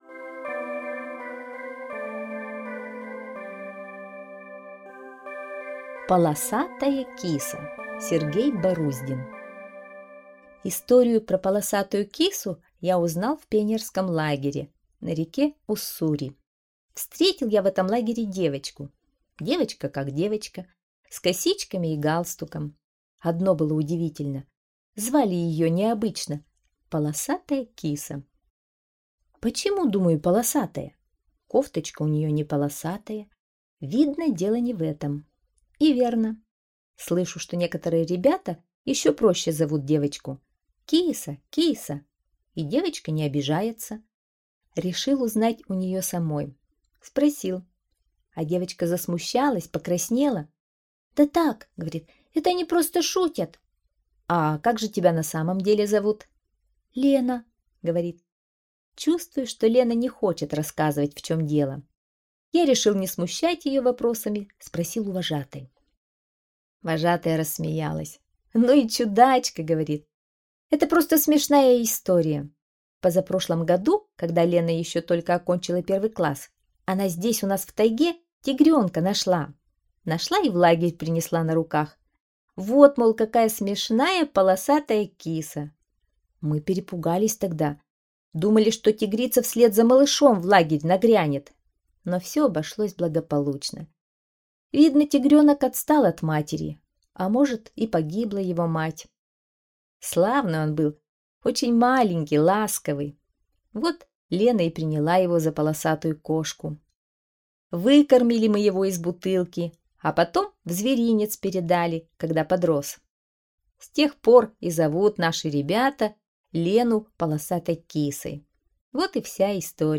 Аудиорассказ «Полосатая киса»